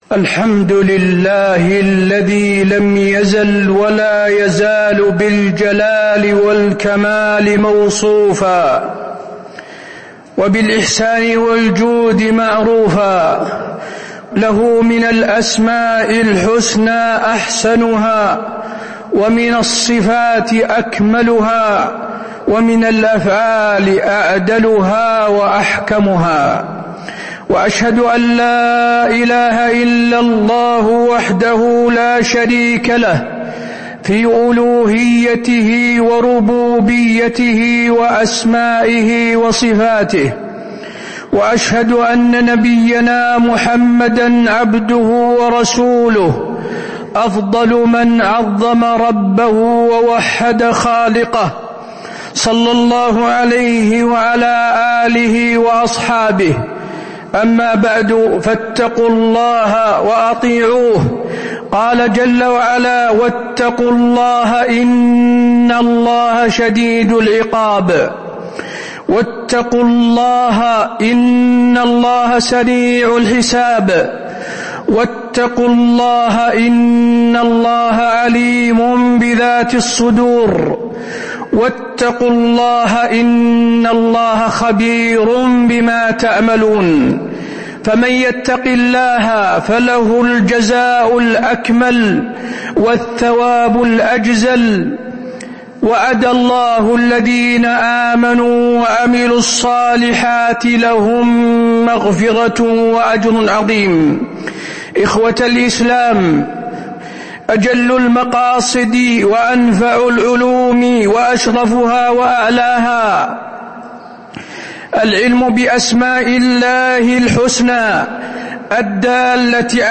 تاريخ النشر ٩ ذو القعدة ١٤٤٥ هـ المكان: المسجد النبوي الشيخ: فضيلة الشيخ د. حسين بن عبدالعزيز آل الشيخ فضيلة الشيخ د. حسين بن عبدالعزيز آل الشيخ فضل معرفة أسماء الله الحسنى